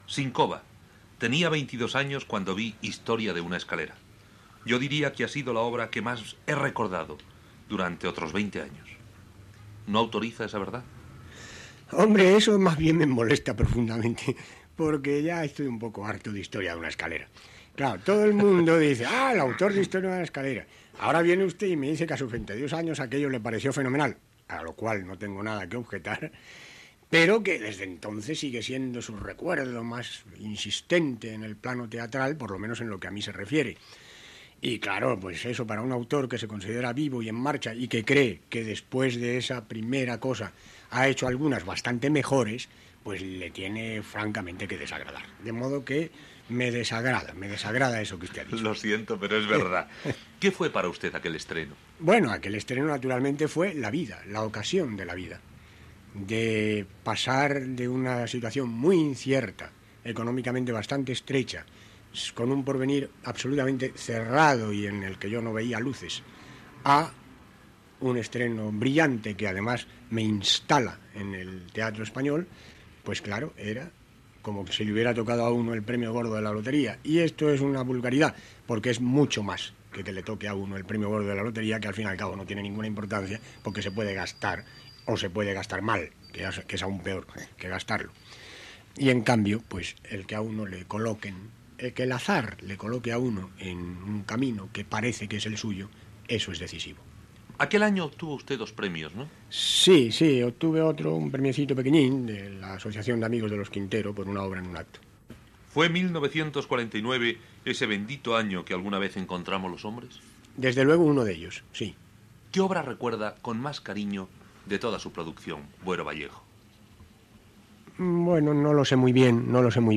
Entrevista a l'escriptor Antonio Buero Vallejo, qui a l'any 1949 va estrenar, al Teatre Espanyol de Madrid, l'obra 'Historia de una escalera'. Recorda aquella estrena i la d'una altra obra seva que considera millor